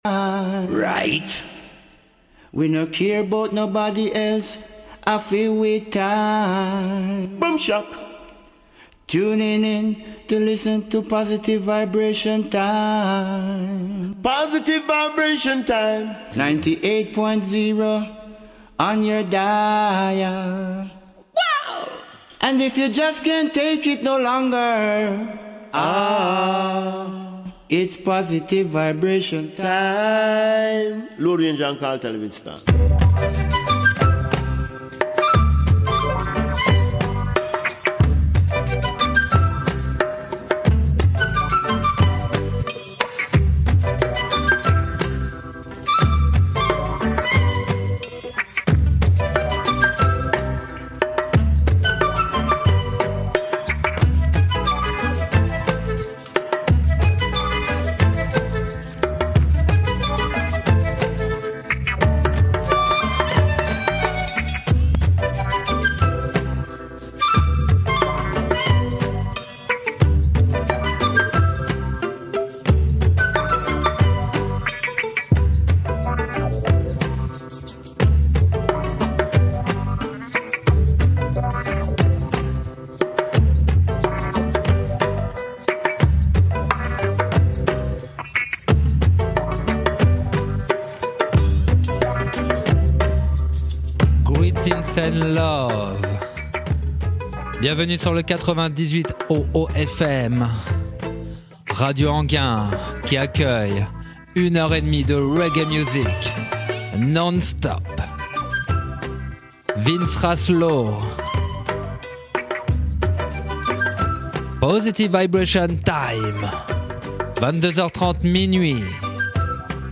reggae classic
nu roots